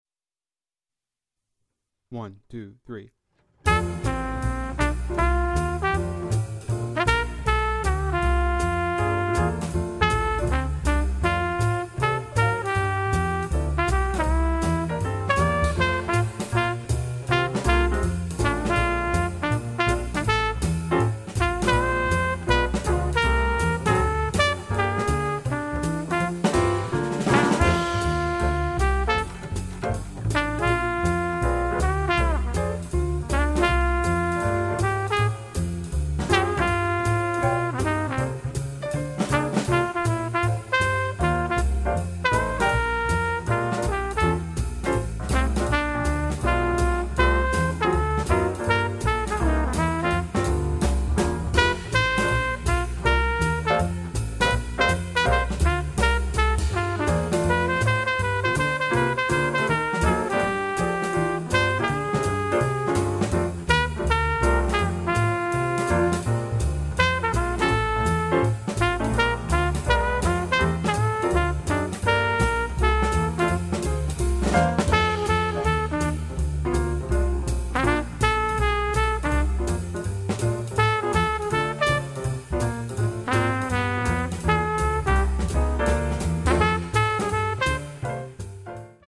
Voicing: Jazz Keyboard